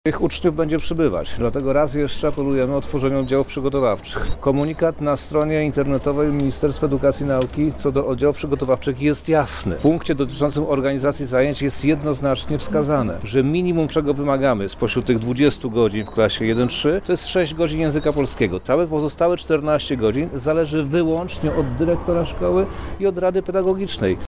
Prawie 8000 uczniów jest w oddziałach przygotowawczych – mówi minister edukacji i nauki Przemysław Czarnek: